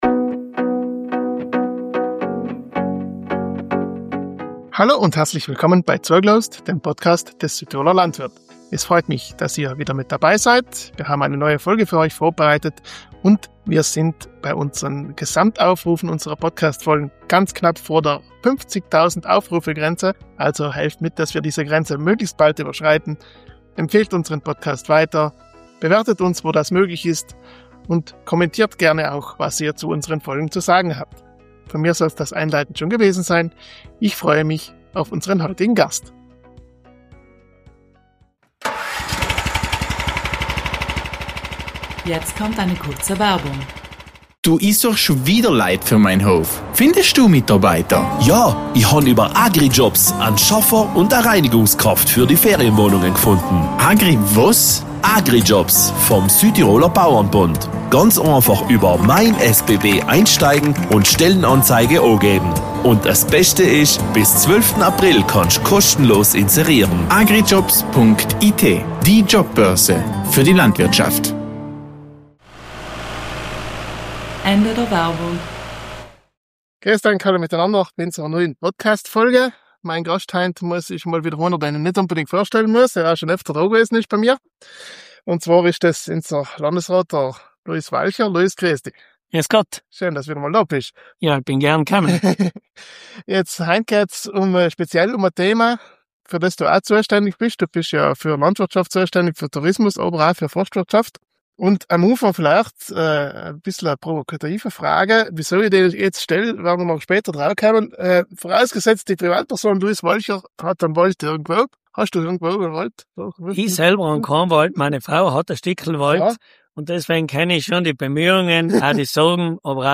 In dieser Folge sprechen wir mit Luis Walcher, Landesrat unter anderem für Forstwirtschaft.